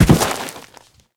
anonGardenFall.ogg